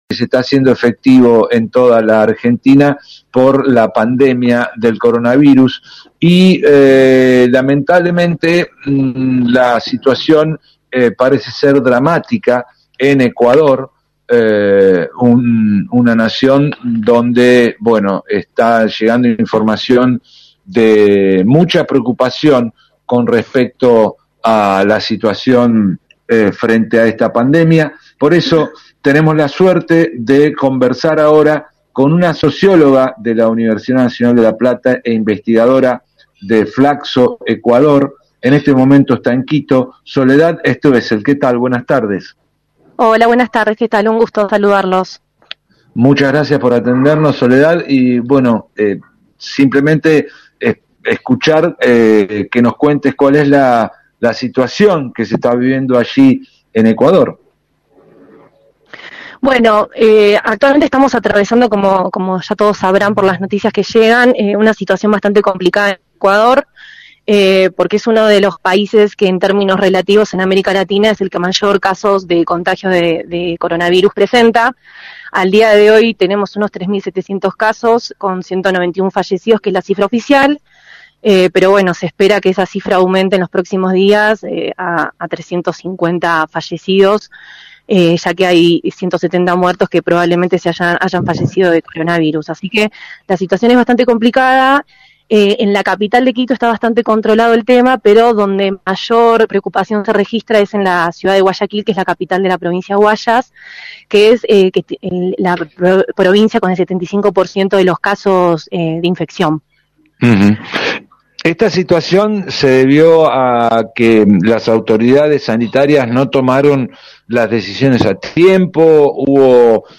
El mercado o la vida: entrevista